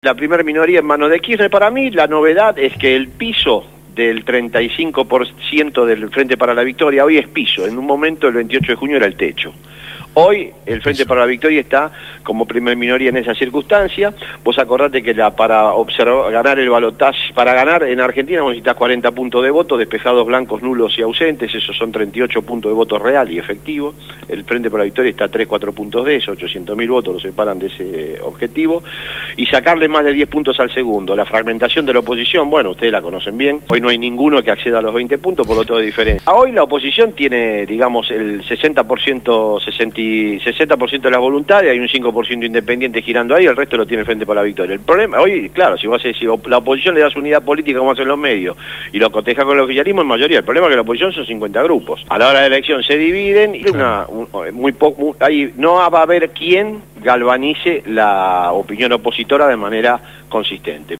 Verborrágico, locuaz.